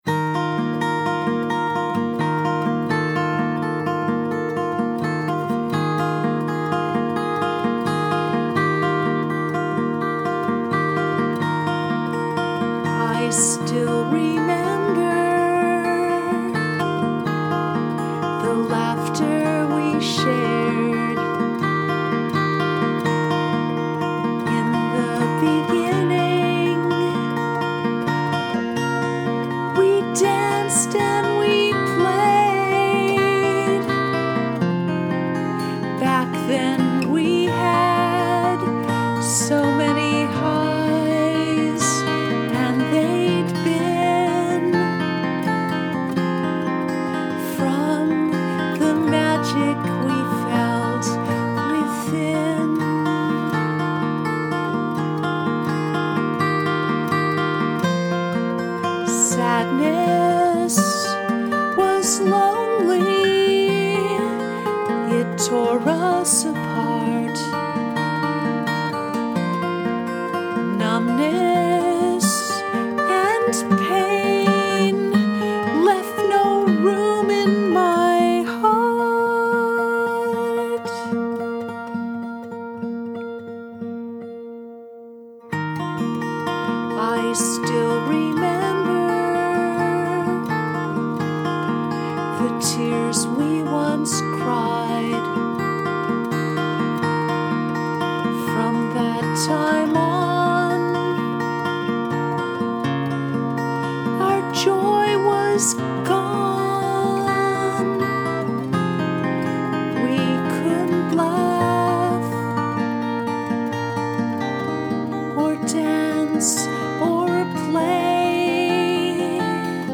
Laughter and Tears Home Recording 2016